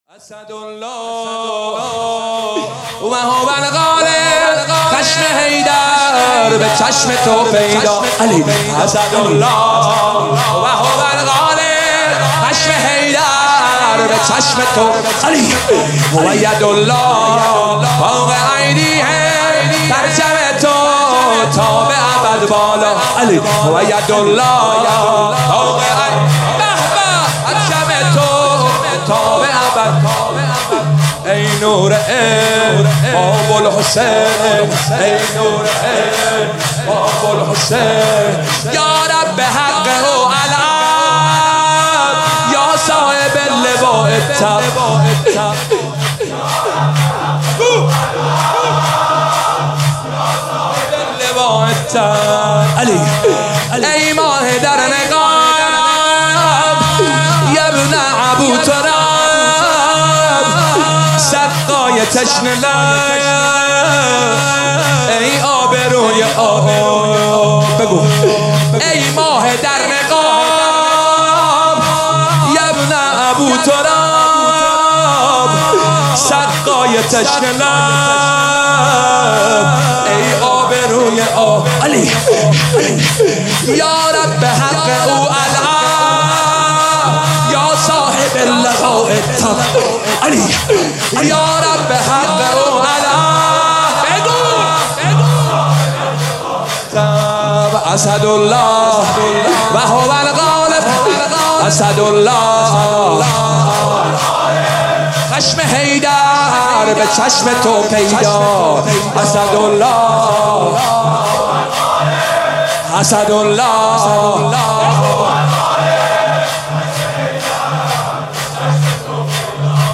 شور بسیار زیبا